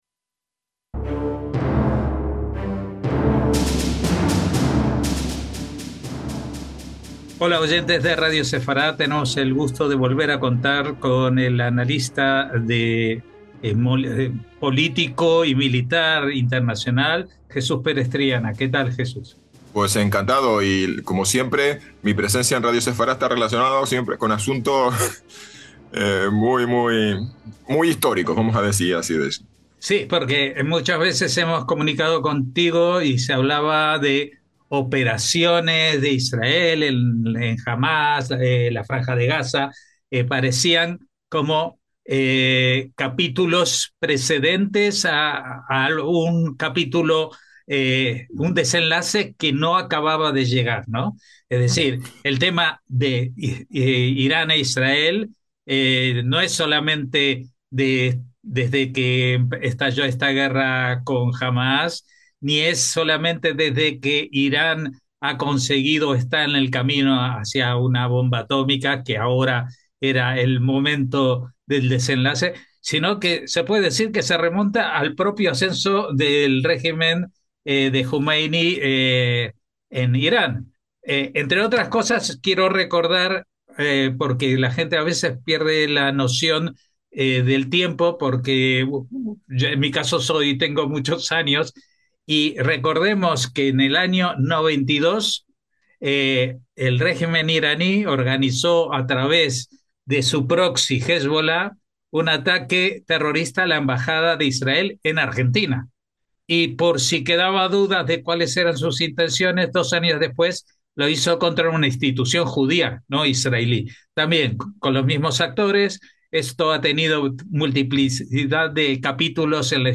Hablamos con el analista de defensa